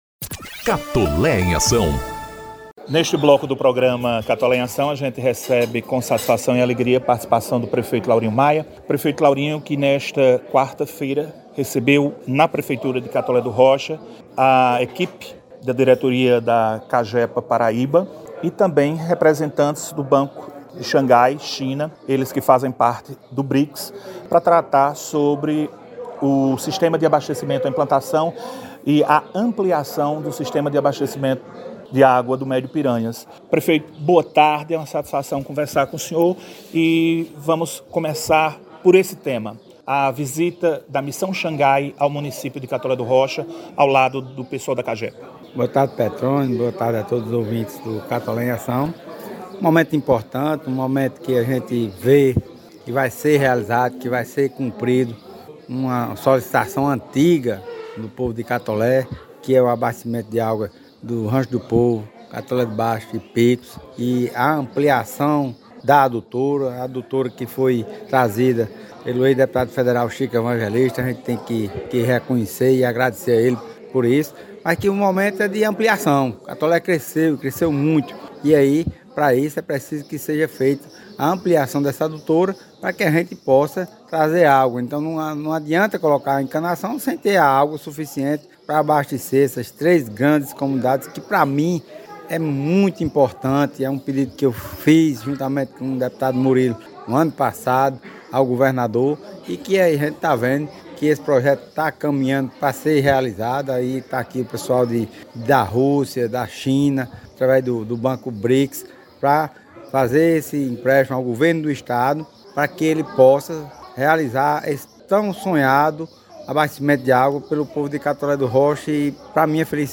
PMCR e CAGEPA reforçam parceria. Ouça a entrevista com o Prefeito Laurinho e Presidente da CAGEPA Marcus Vinícius - Folha Paraibana
F-Entrevista-com-o-Prefeito-Laurinho-Maia-e-o-Pres.-Marcus-Vinicius-CAGEPA.mp3